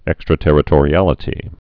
(ĕkstrə-tĕrĭ-tôrē-ălĭ-tē)